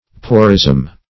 porism - definition of porism - synonyms, pronunciation, spelling from Free Dictionary
Porism \Po"rism\, n. [Gr.